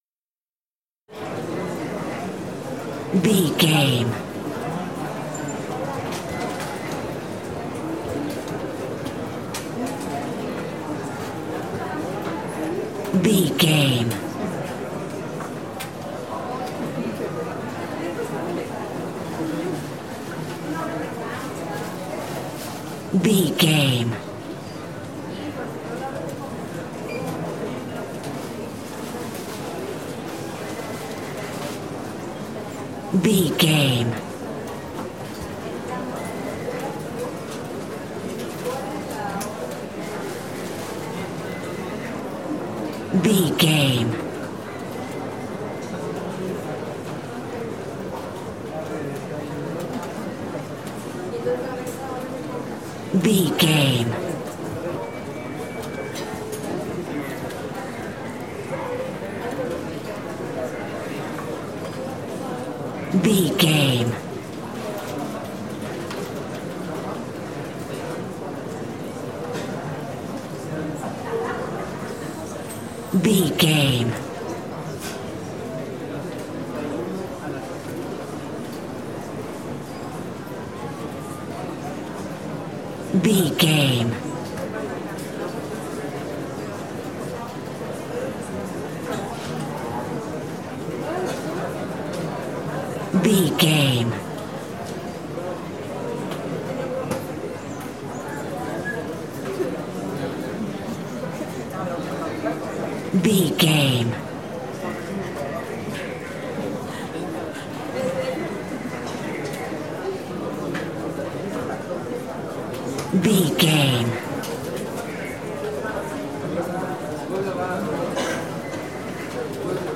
Telemarketing office large crowd
Sound Effects
urban
chaotic
ambience